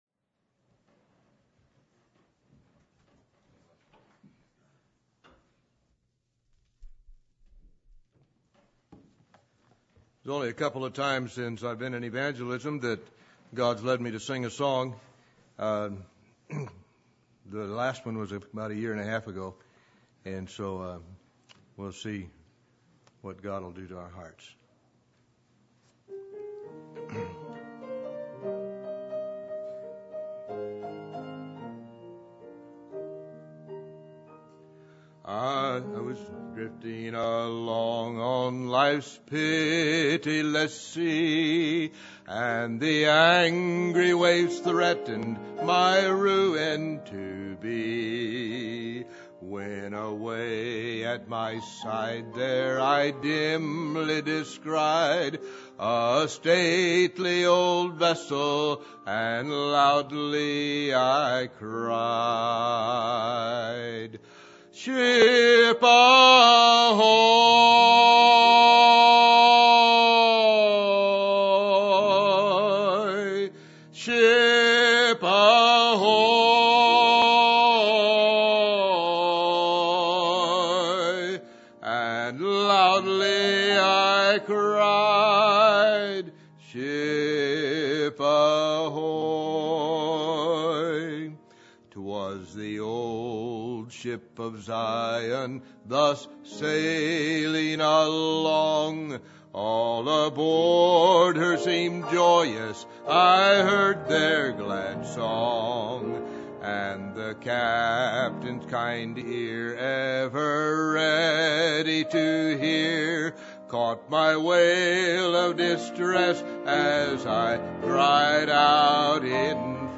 Passage: Romans 7:15-25 Service Type: Revival Meetings